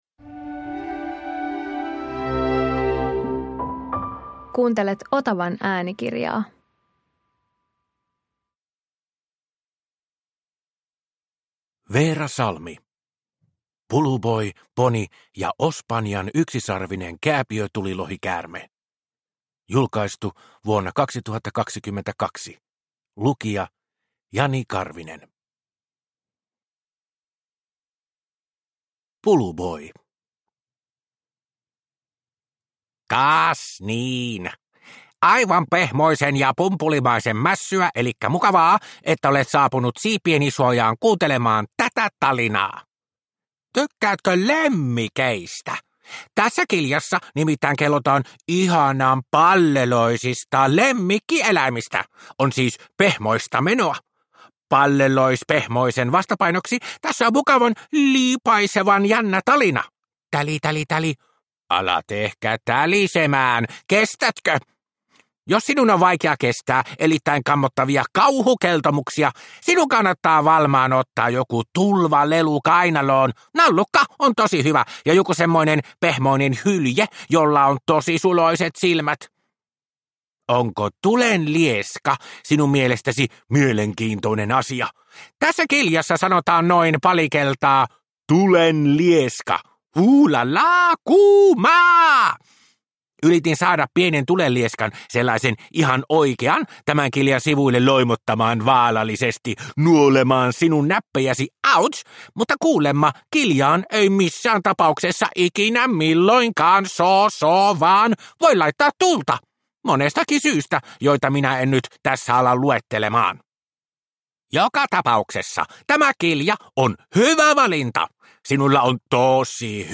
Puluboi, Poni ja Ospanjan yksisarvinen kääpiötulilohikäärme – Ljudbok – Laddas ner